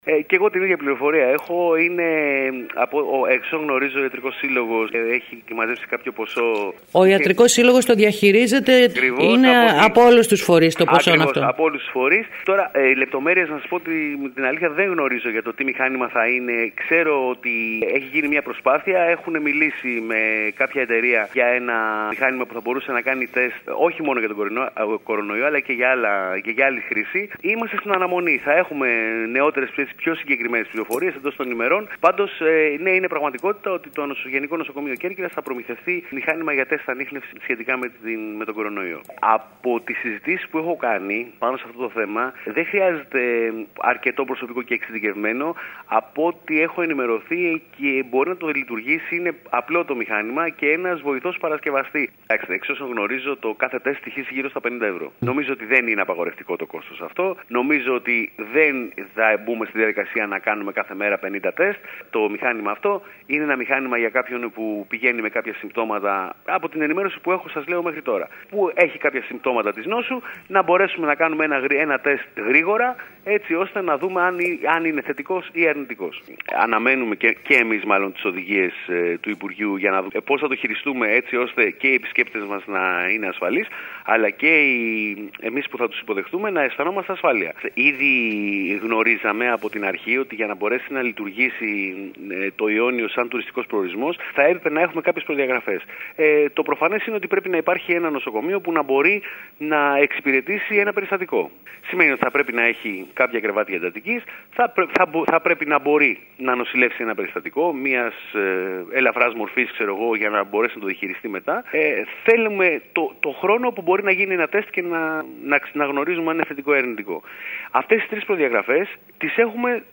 Την πληροφορία αυτή επιβεβαιώνει και ο Αντιπεριφερειάρχης Κώστας Ζορμπάς ο οποίος μιλώντας σήμερα στην ΕΡΑ ΚΕΡΚΥΡΑΣ τόνισε ότι, με βάση τις μέχρι σήμερα πληροφορίες, το Ιόνιο ως τουριστικός προορισμός θα καταταχθεί στην πρώτη κατηγορία αφού πληρεί και τις τρεις προϋποθέσεις που μέχρι σήμερα είναι γνωστές.